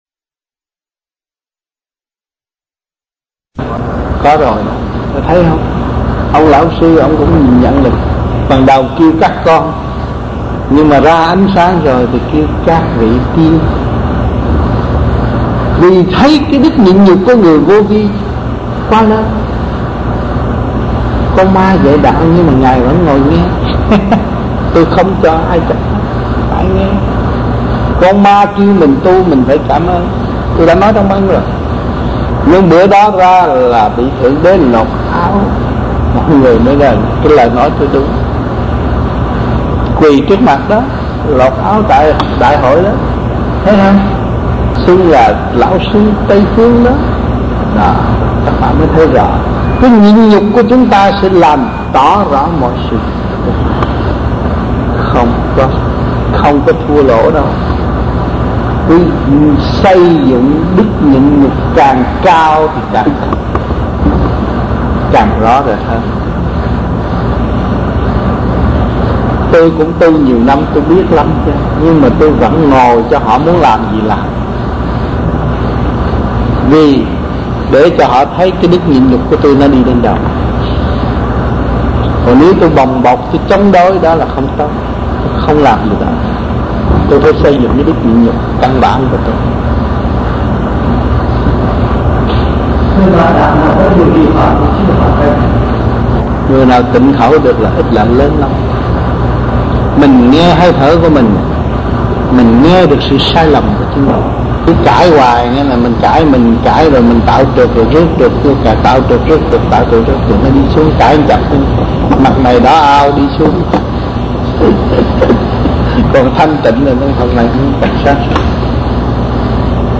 Washington DC, United States Trong dịp : Giảng cho cộng đồng >> wide display >> Downloads